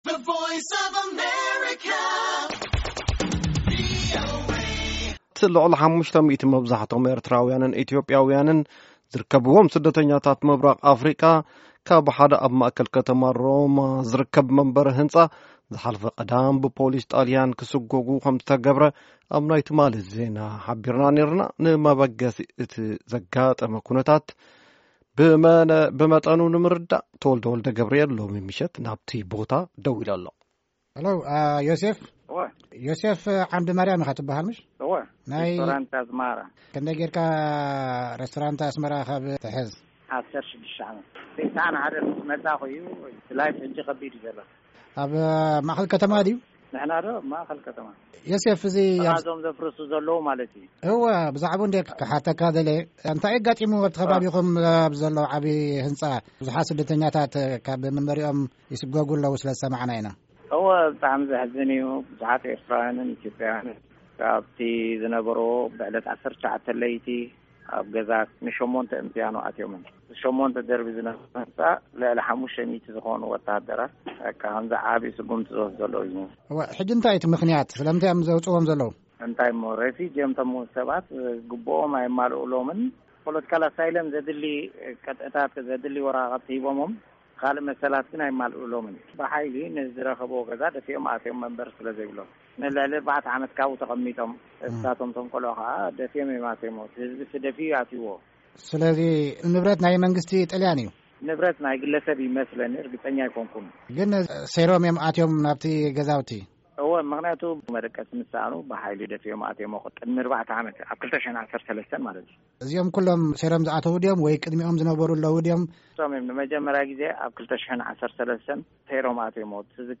ነቲ ዘጋጠመ ኩነታት ብዝምልከት ንገለ ኣብቲ ቦታ ዘለው ሰባት አዘራሪብና አለና፡ ምሉእ ትሕዝቶ ኣብዚ ምስማዕ ይክኣል።